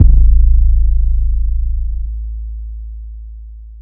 808s
SPINZ TM SIZ 808_1.wav